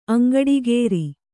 ♪ aŋgaḍigēri